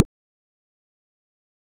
EDM Kick 50.wav